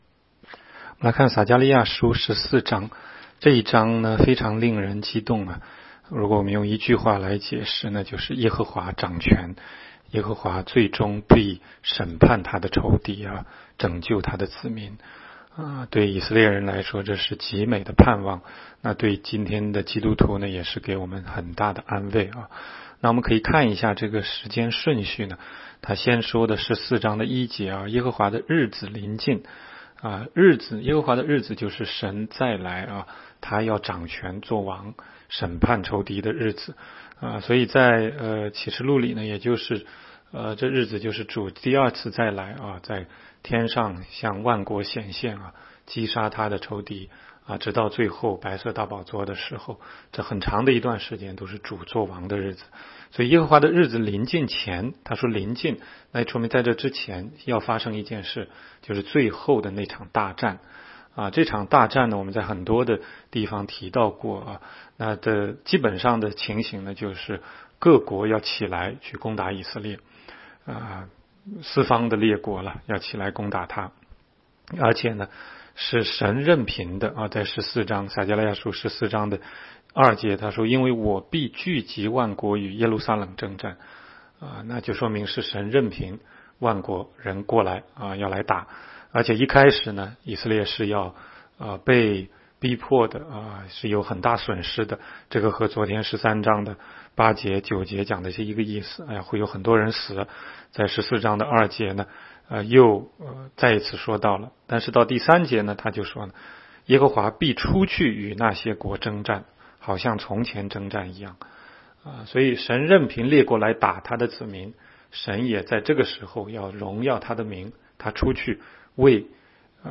16街讲道录音 - 每日读经 -《撒迦利亚书》14章